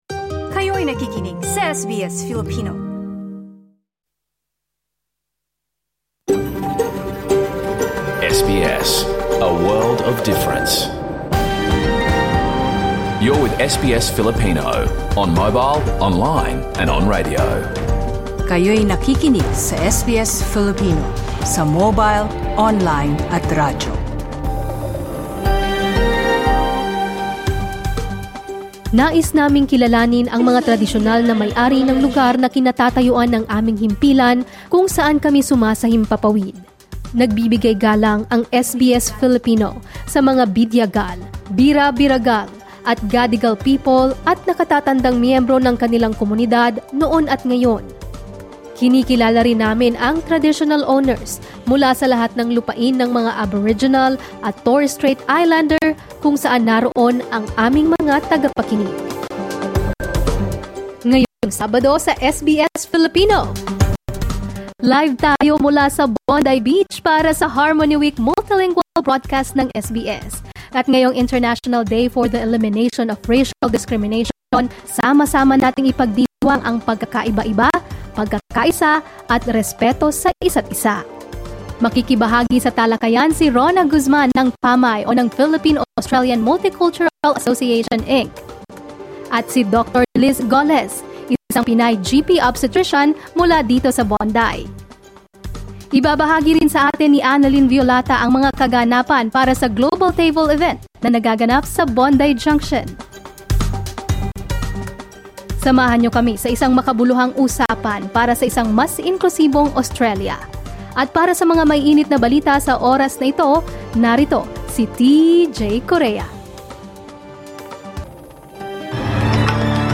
SBS Filipino is one of the language programs that broadcasts live in Bondi Pavilion alongside Italian, Mandarin, Hebrew, Arabic and other language services this March 21.